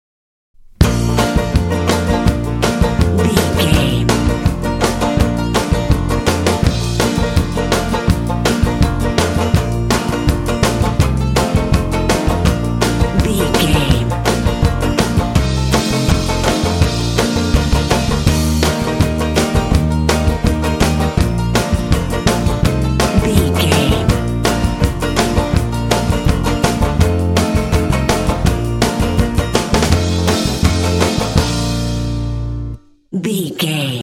This track will give your game a cheerful country feel.
Ionian/Major
B♭
happy
bouncy
bright
drums
bass guitar
acoustic guitar
country